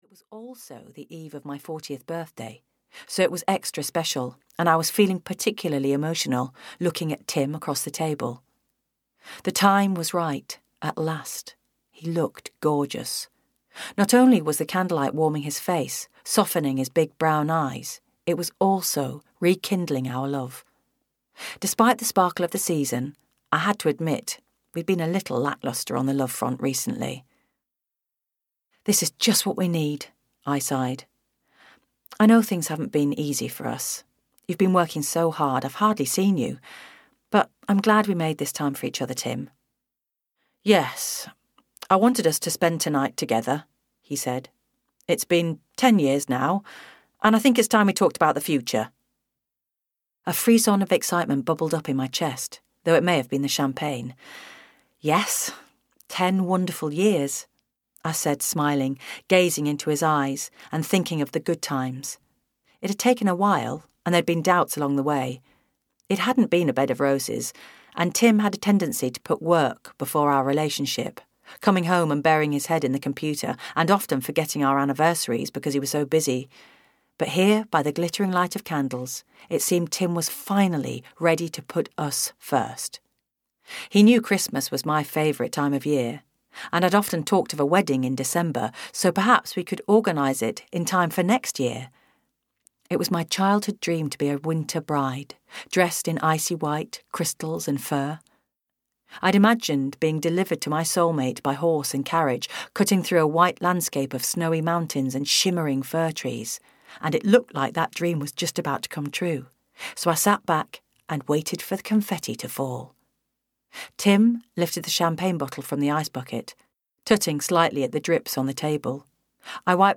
The Christmas Cake Cafe (EN) audiokniha
Ukázka z knihy